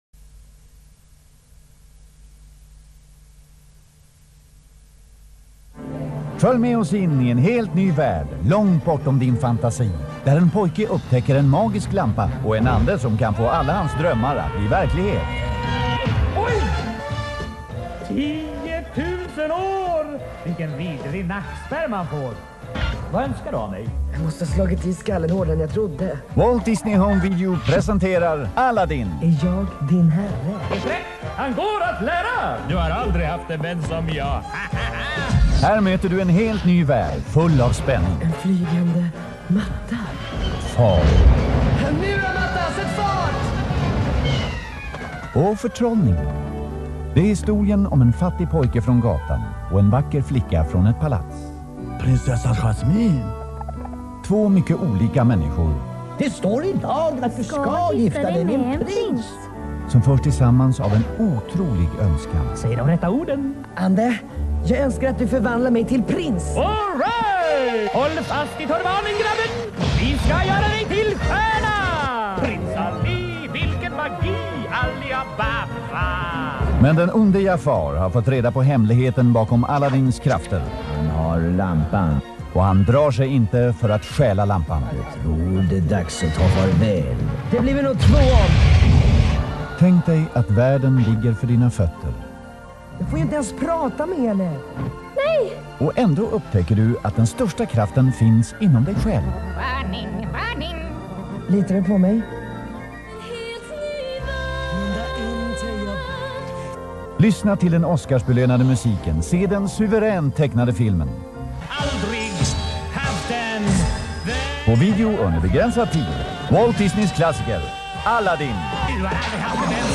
Tecknat Barn Svenska:Aladdin (1992 Walt Disney Home Video AB) VHSRIPPEN (Svenska) Trailer (4D)